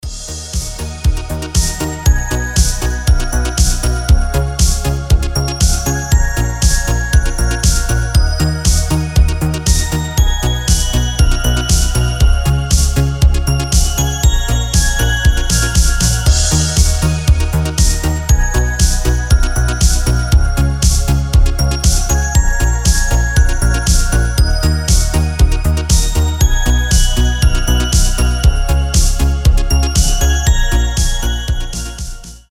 • Качество: 320, Stereo
ритмичные
мелодичные
Synth Pop
без слов